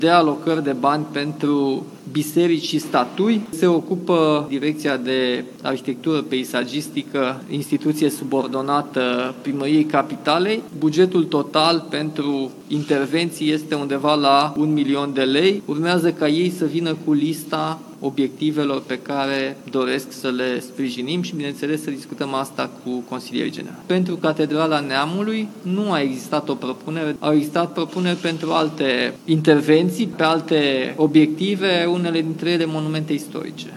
Pentru renovarea altor biserici au fost prevăzute însă fonduri, în schița privind bugetul pentru acest an, a spus primarul general al Bucureștiului, Nicușor Dan: